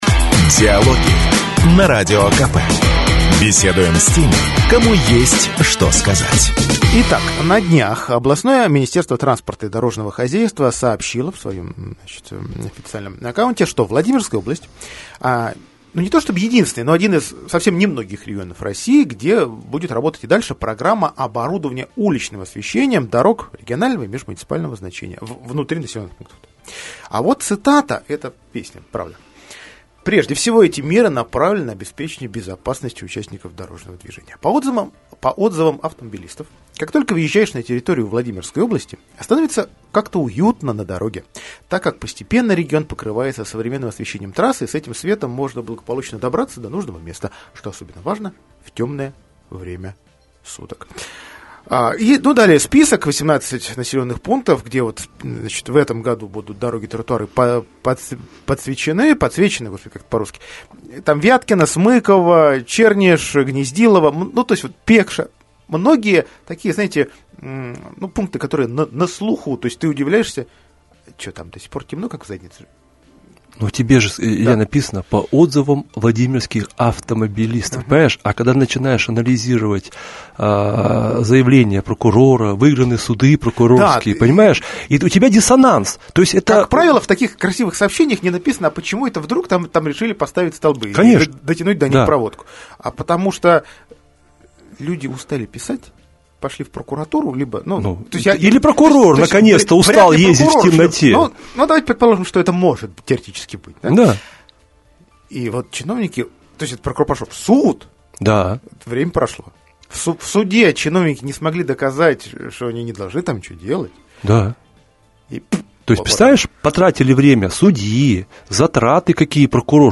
Полная версия интервью – в прикрепленном аудиофайле.